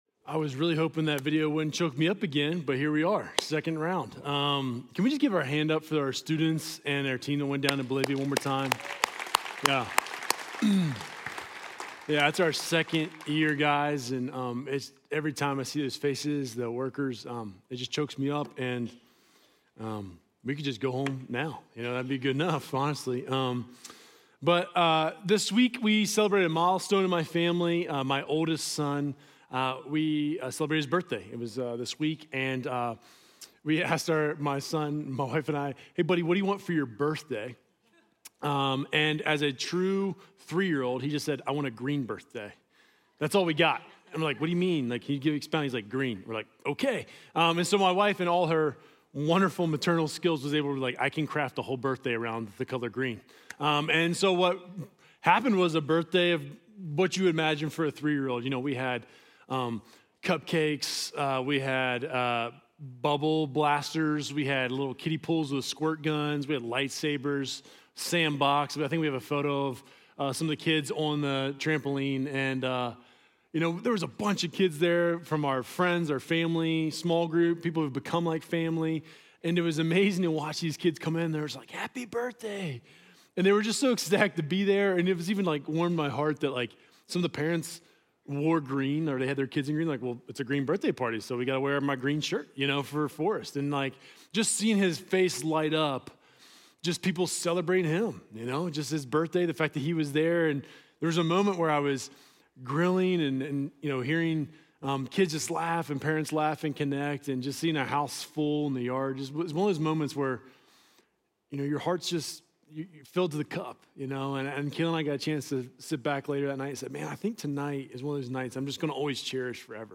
Journey Church Bozeman Sermons Summer In The Psalms: What Do I Treasure?